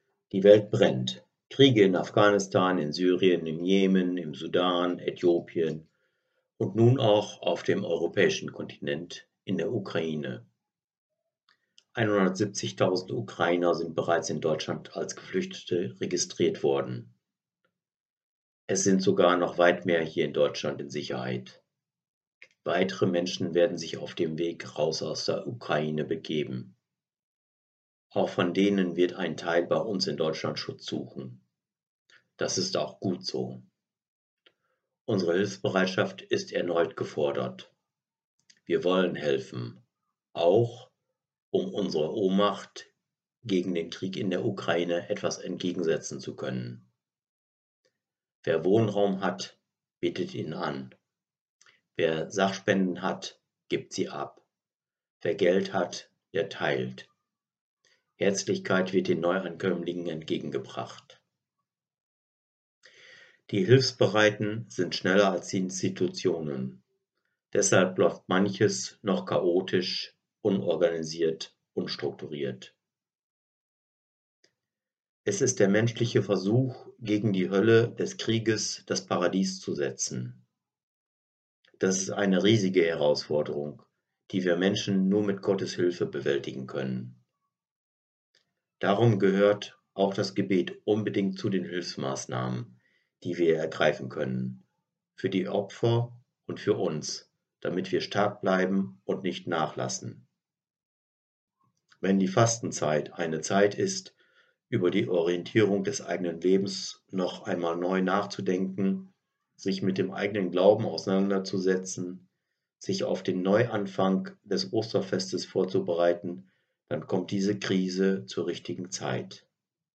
Pastoraler Mitarbeiter.